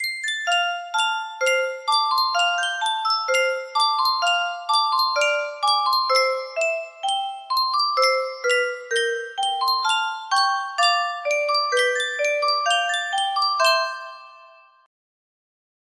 Yunsheng Music Box - You're a Grand Old Flag 2778 music box melody
Full range 60